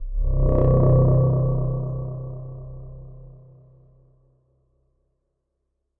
描述：B中的电子轰鸣声
标签： 生物 电子 动物 咆哮 怪物 咆哮
声道立体声